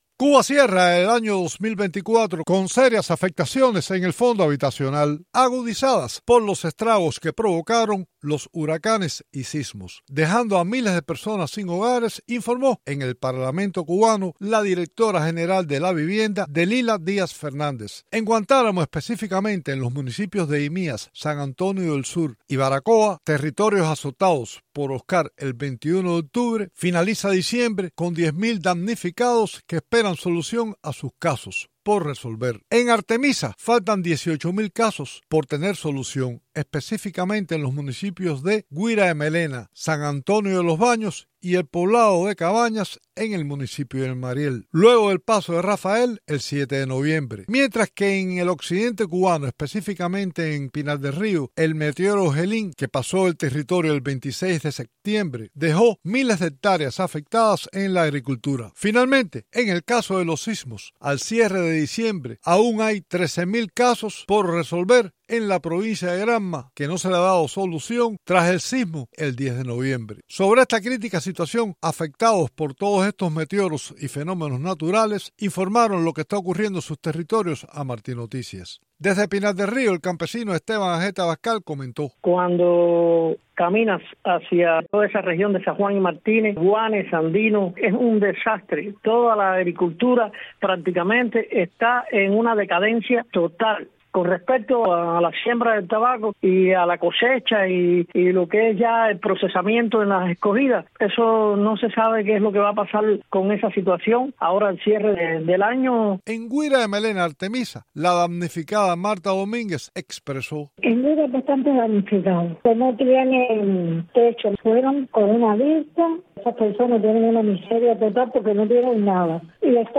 Los desastres naturales que afectaron a Cuba en 2024: Testimonios